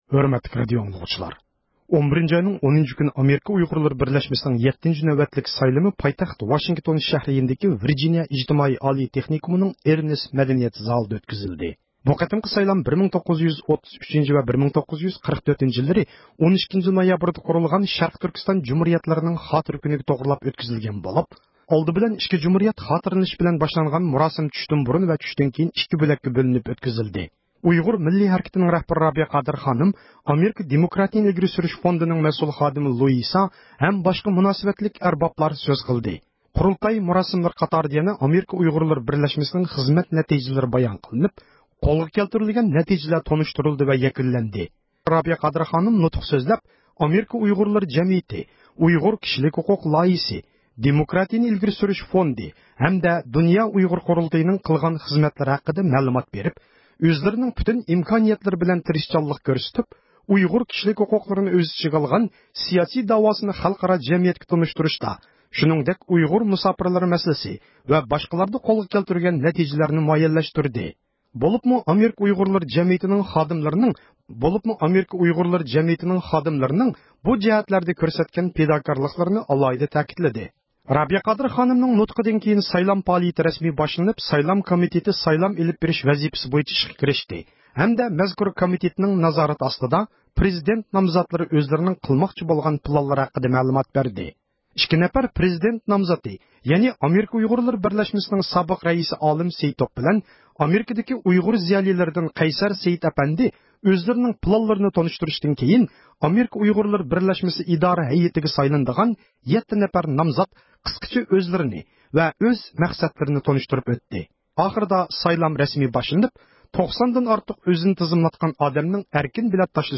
ئامېرىكا ئۇيغۇرلىرى بىرلەشمىسىنىڭ 7 – قېتىملىق قۇرۇلتىيى غەلىبىلىك ئاخىرلاشقاندىن كېيىن، رابىيە قادىر خانىم زىيارىتىمىزنى قوبۇل قىلىپ بۇ قېتىمقى قۇرۇلتاينىڭ غەلىبىلىكى ئېچىلغانلىقىنى كۆرسەتتى.